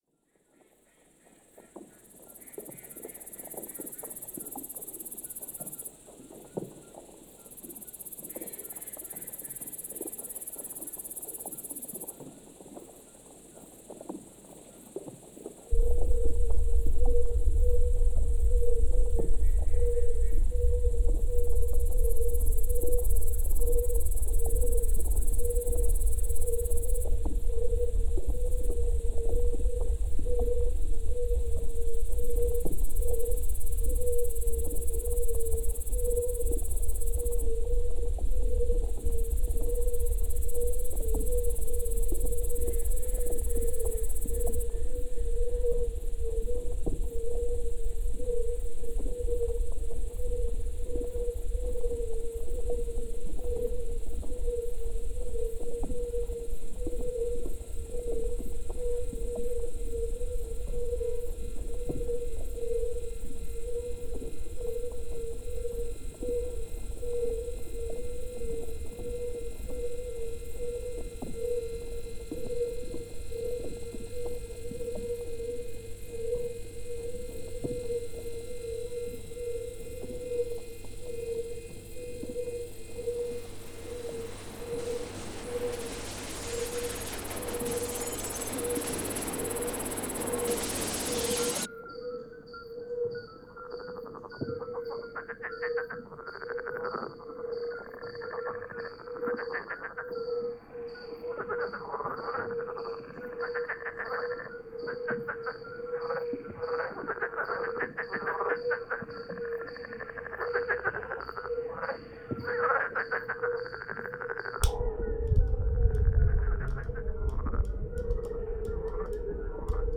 based on sounds of insects
Soundscape Series